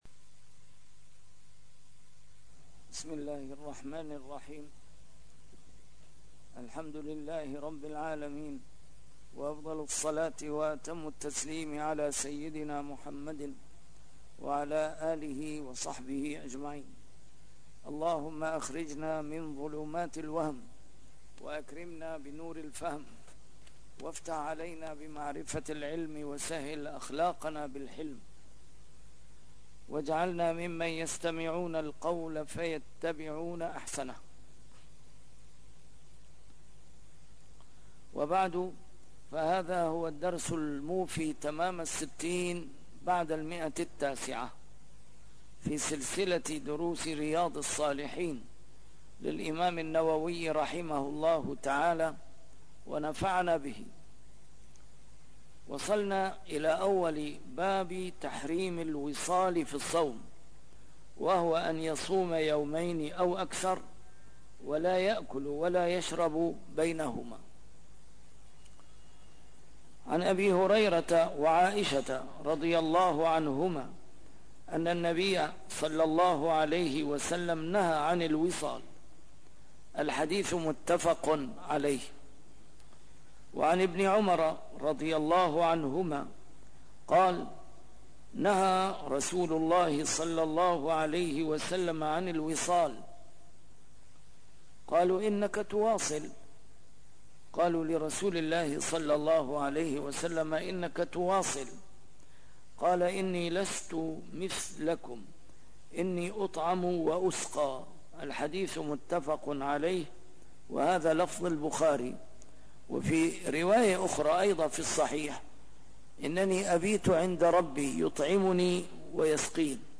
A MARTYR SCHOLAR: IMAM MUHAMMAD SAEED RAMADAN AL-BOUTI - الدروس العلمية - شرح كتاب رياض الصالحين - 960- شرح رياض الصالحين: تحريم الوصال في الصوم